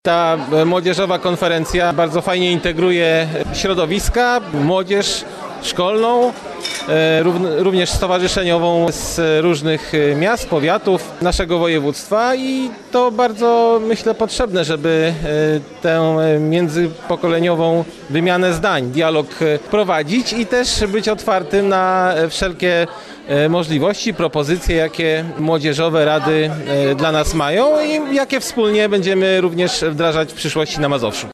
W Warszawskiej Operze Kameralnej miała miejsce pierwsza Konferencja Młodzieżowych Rad.
Przewodniczący Sejmiku Województwa Mazowieckiego Ludwik Rakowski podkreślił, jak ważne są wspólne inicjatywy.